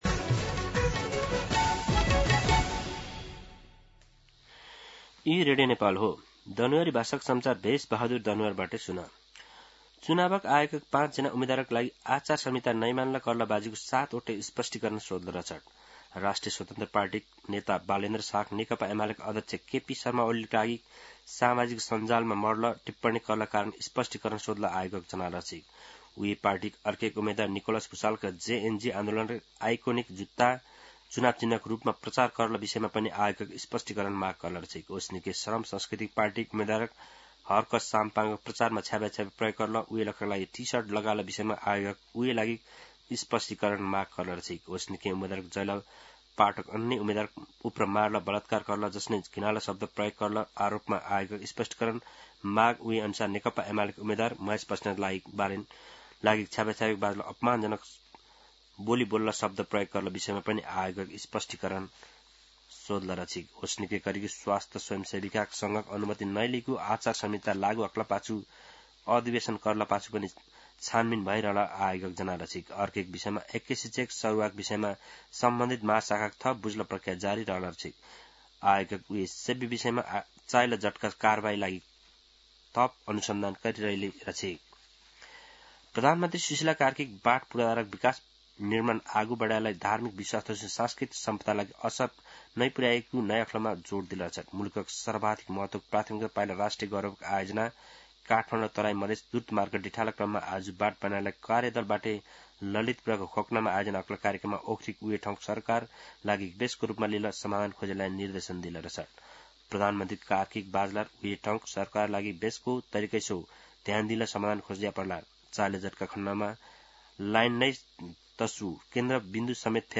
दनुवार भाषामा समाचार : १३ माघ , २०८२
Danuwar-News-13.mp3